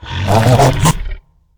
alien_language_02.ogg